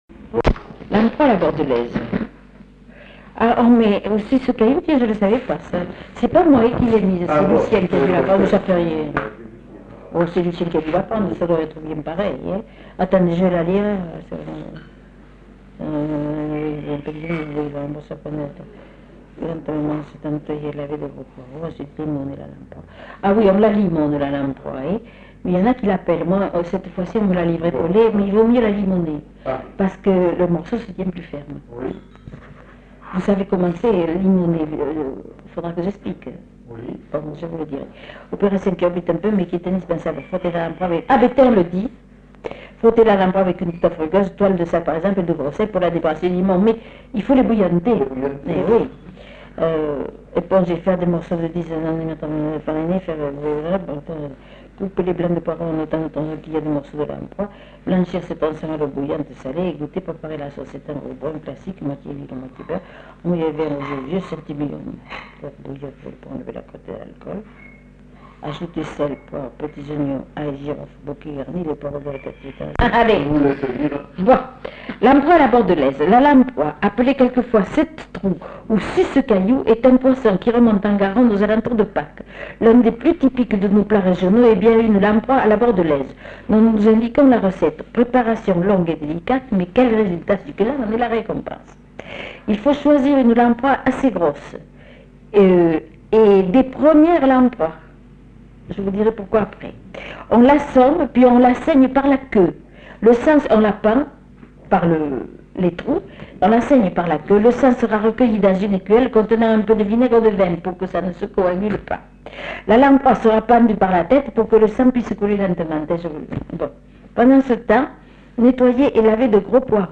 Lieu : La Réole
Genre : témoignage thématique